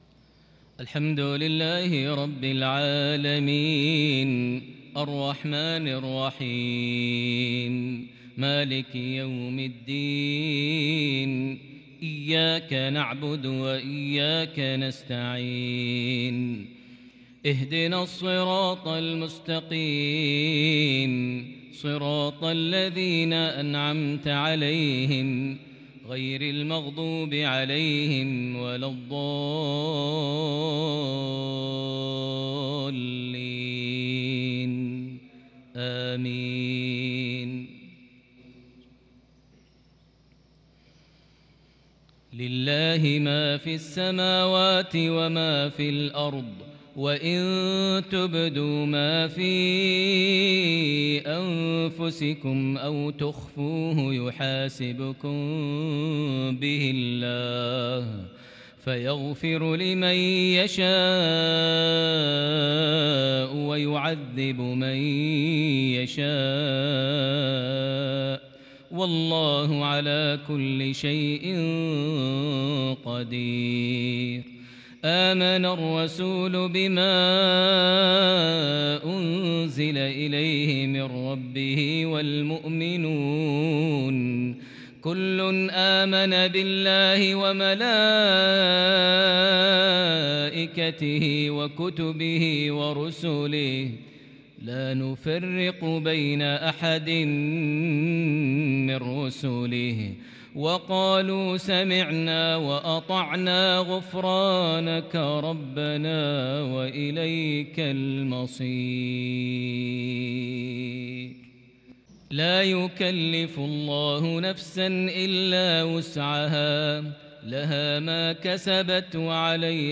مغربية فريدة بالكرد لخواتيم سورة البقرة | 26 ربيع الأول 1442هـ > 1442 هـ > الفروض - تلاوات ماهر المعيقلي